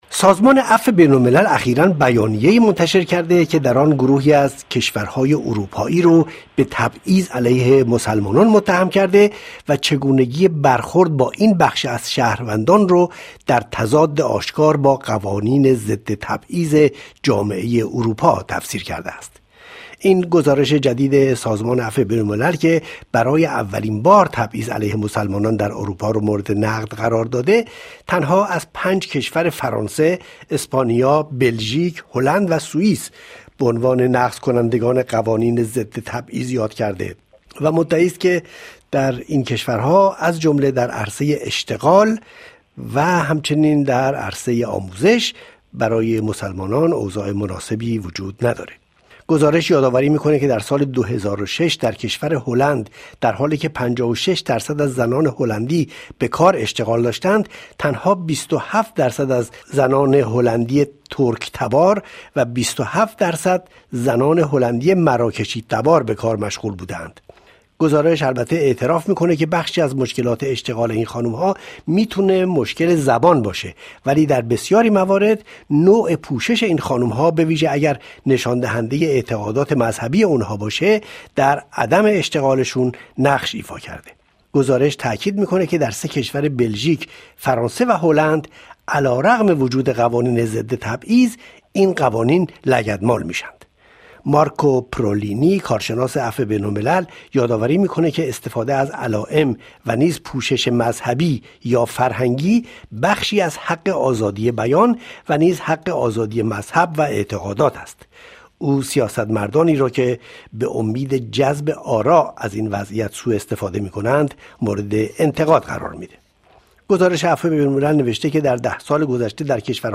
در یک میز گرد رادیوئی به همین مناسبت، از سه نفر از جامعه شناسان ایرانی مقیم غرب دعوت کرده ایم تا نقد سازمان عفو بین الملل درباره رفتار کشورهای غربی نسبت به مسلمانان اروپائی را به بحث و تبادل نظر بگذاریم.
Table_Ronde_06_05.mp3